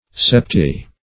Search Result for " septi-" : The Collaborative International Dictionary of English v.0.48: Septi- \Sep"ti-\, [L. septem seven.] A combining form meaning seven; as, septifolious, seven-leaved; septi-lateral, seven-sided.